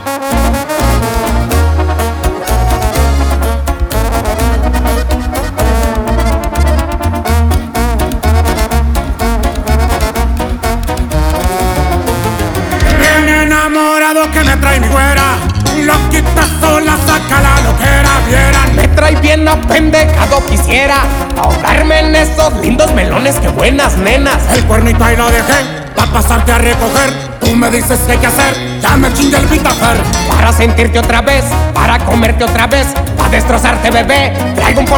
Música Mexicana Latin